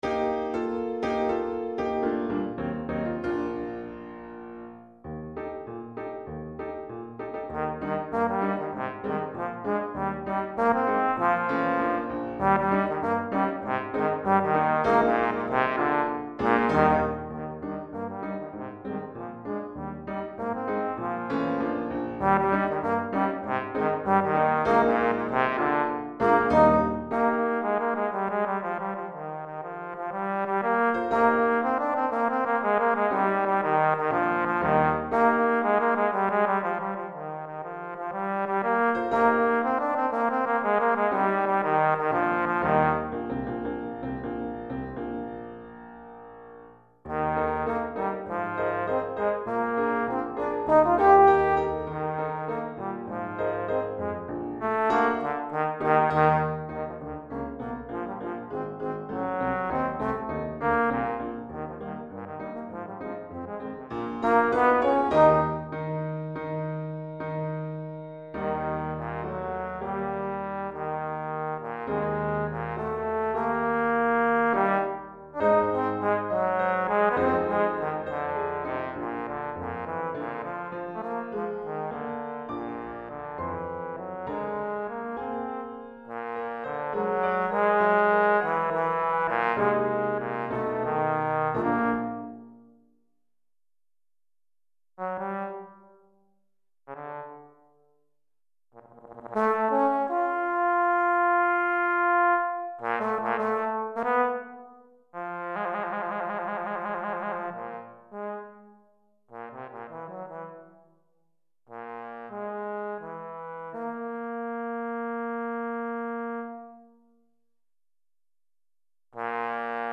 Trombone et Piano